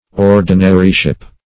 Search Result for " ordinaryship" : The Collaborative International Dictionary of English v.0.48: Ordinaryship \Or"di*na*ry*ship\, n. The state of being an ordinary.